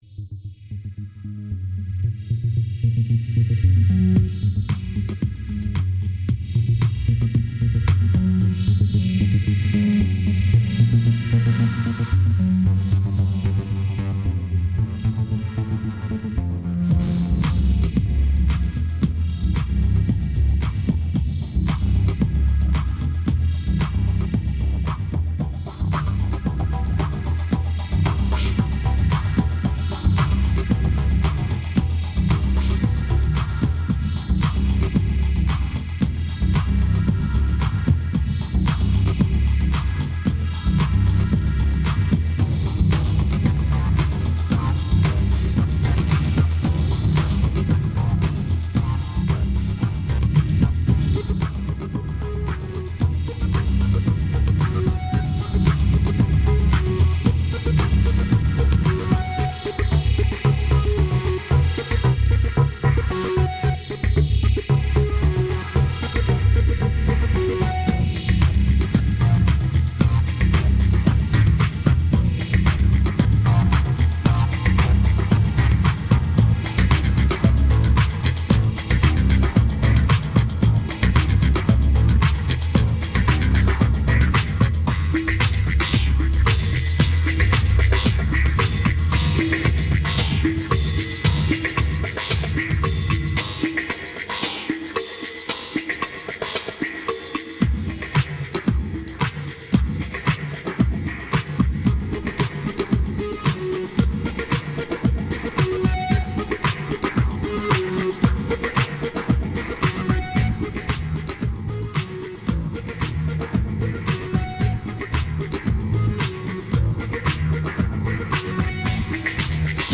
2278📈 - 39%🤔 - 113BPM🔊 - 2009-09-10📅 - -29🌟